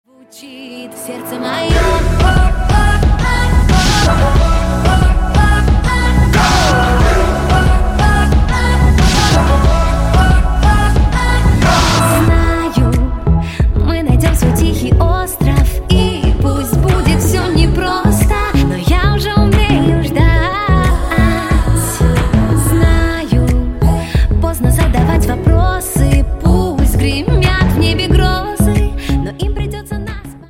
• Качество: 128, Stereo
поп
dance
Electronic
vocal